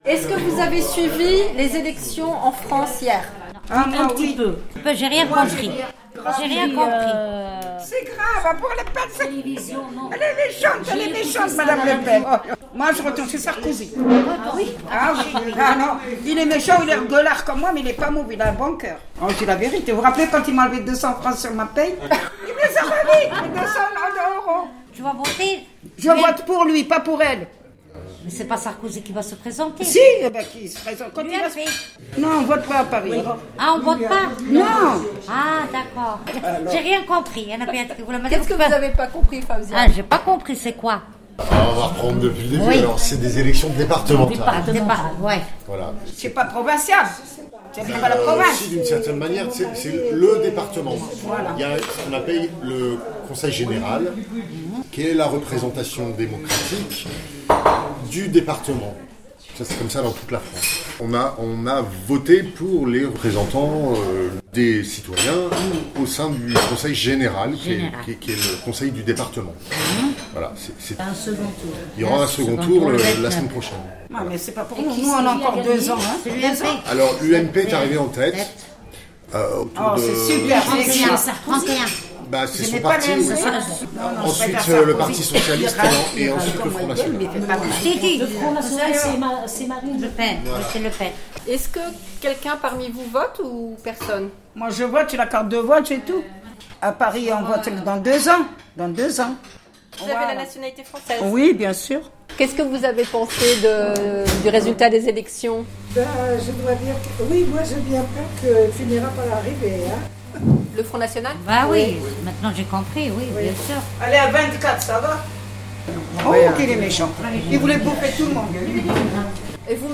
Elles ont ensuite commenté les élections en France et exprimé leur crainte face à la montée du Front National.
Ces rencontres ont lieu deux fois par mois au café social, le lundi à 15h.